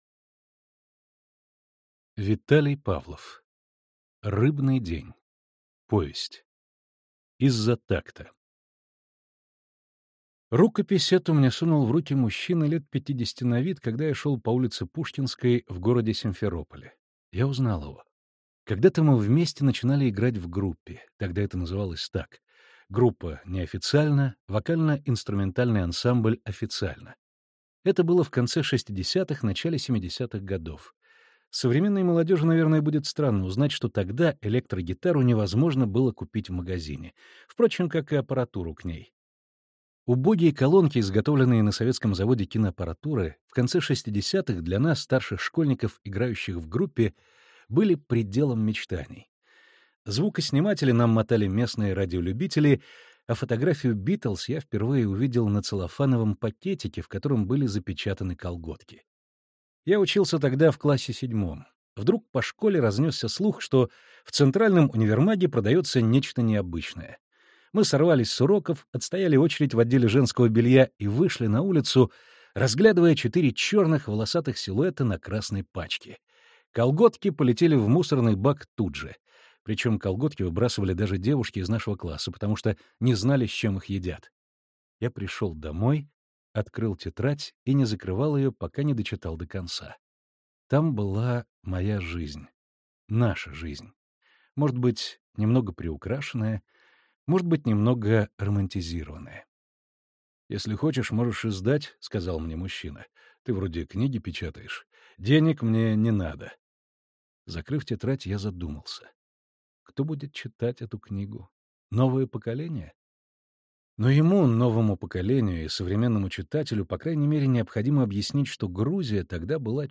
Аудиокнига Рыбный день. Повесть | Библиотека аудиокниг